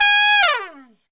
Index of /90_sSampleCDs/Best Service ProSamples vol.25 - Pop & Funk Brass [AKAI] 1CD/Partition C/TRUMPET FX3